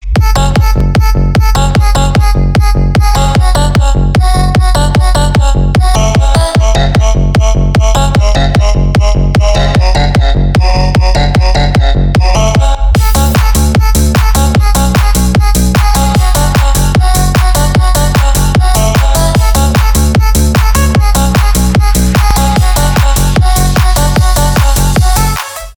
клубные , техно